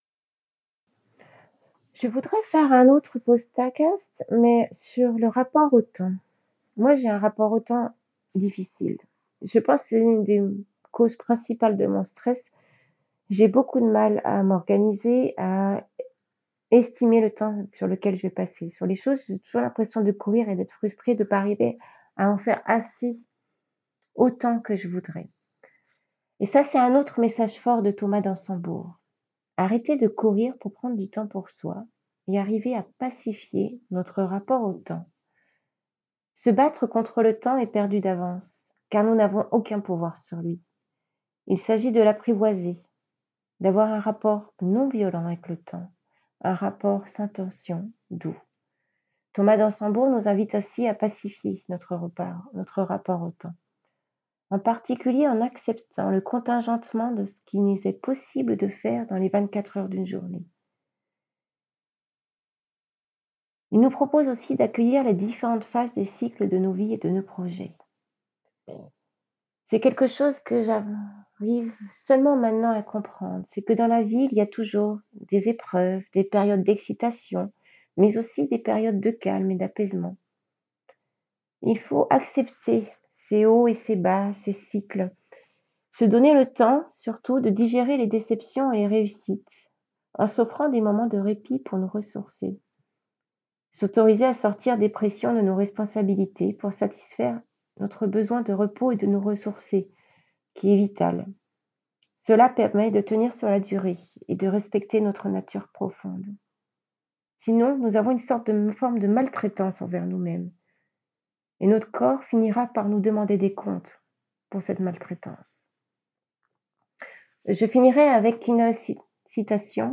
Des exemples très simples, improvisés et presque sans montage.